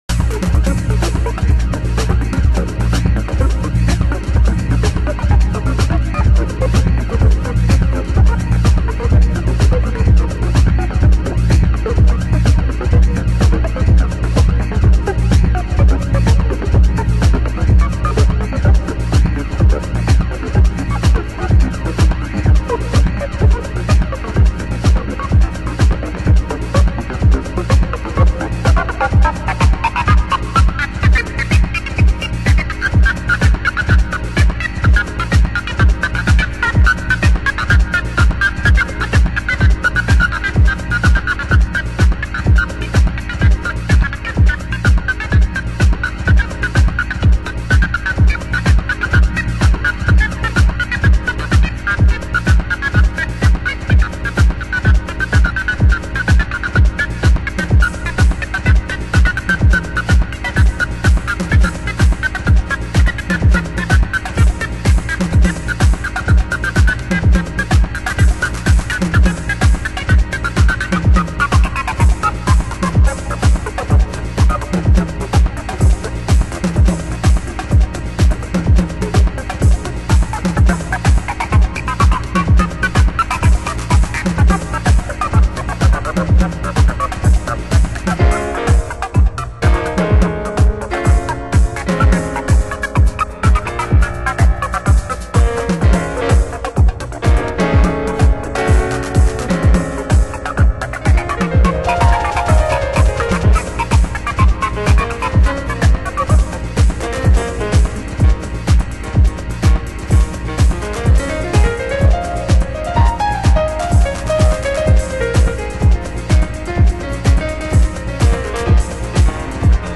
HOUSE MUSIC
盤質：A面に軽いスレ傷 、少しチリパチノイズ有